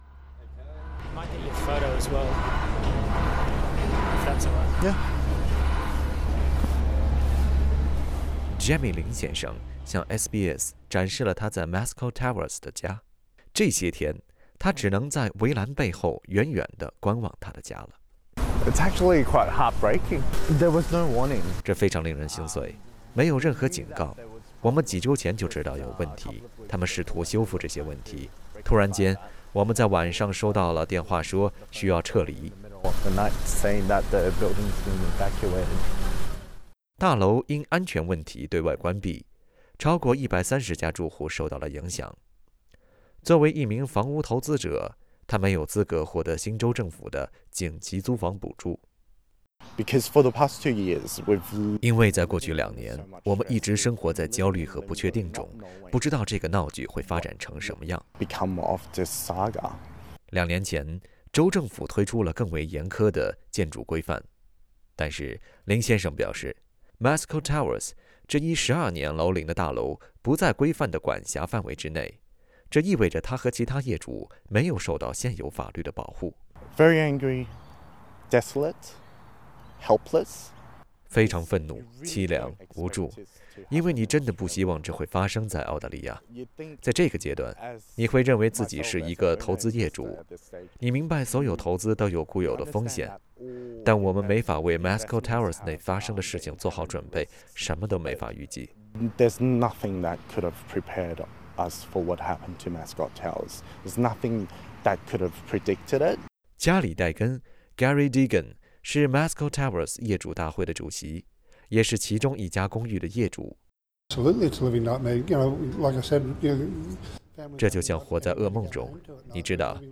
悉尼Mascot Towers在2019年遭遇结构性损坏，大楼住户因安全问题被迫撤离，至今，依然没有着落。 （点击音频，收听完整采访）